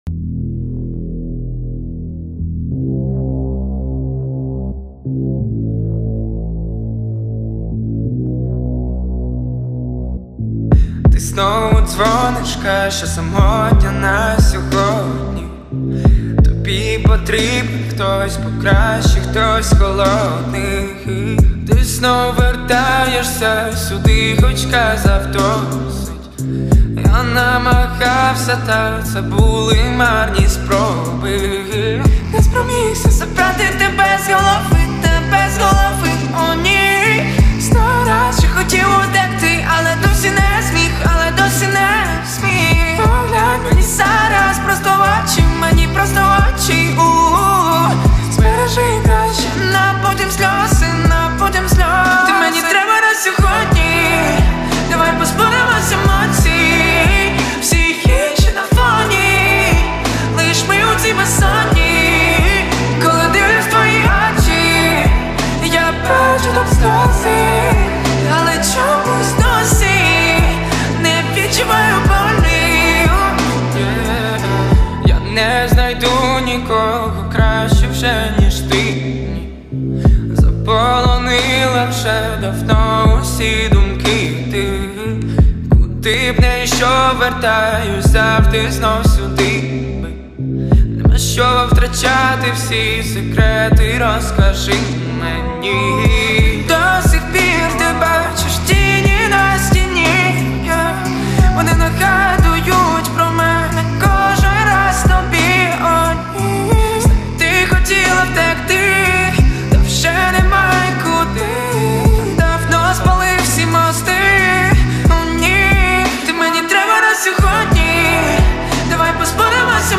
• Жанр:RnB